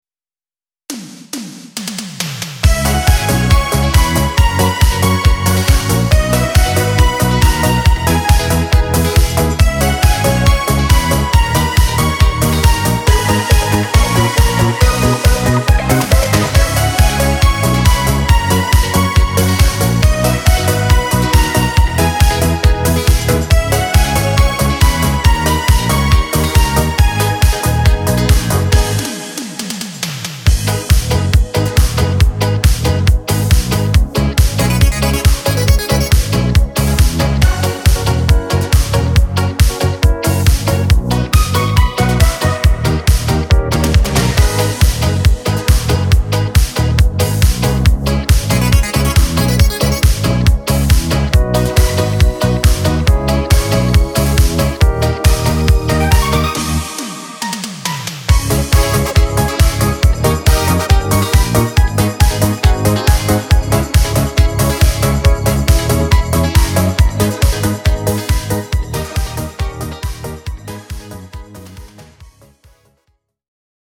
podkład muzyczny dla wokalistów
Disco Polo